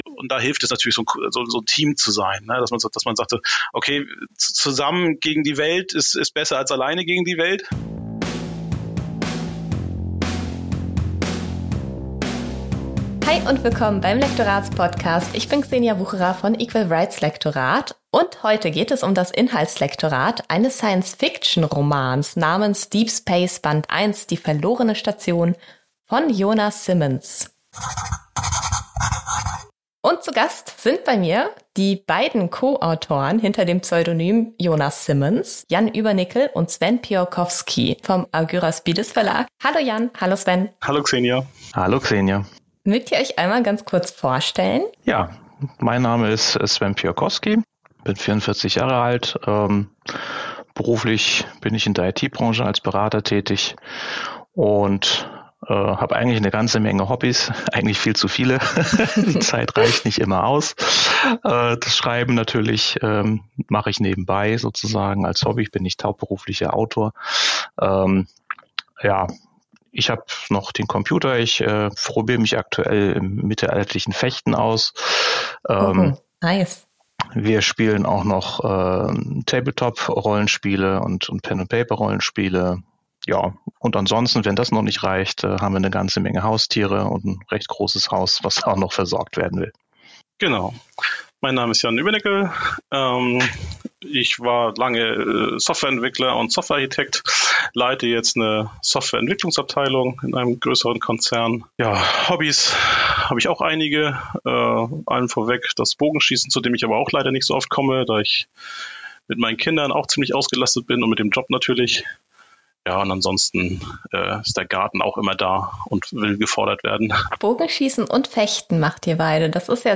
#10 Lektorin interviewt Co-Autoren & Verlag